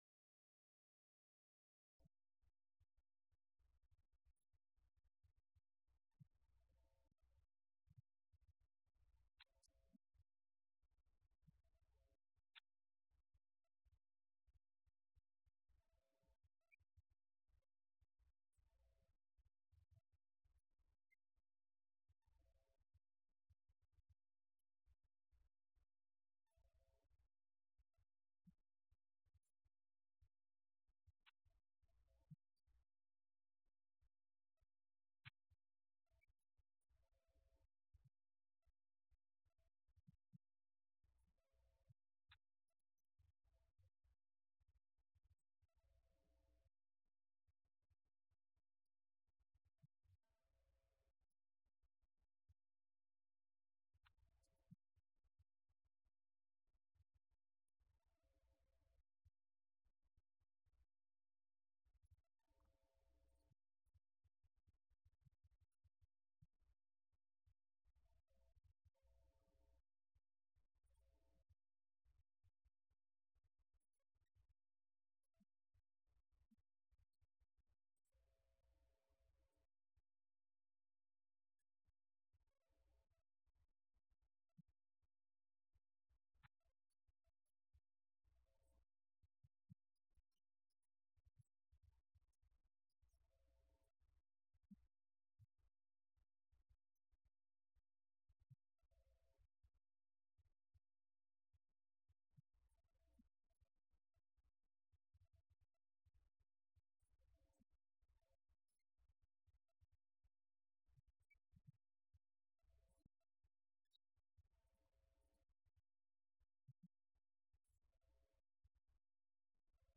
Series: Schertz Lectureship Event: 17th Annual Schertz Lectures Theme/Title: Studies in Job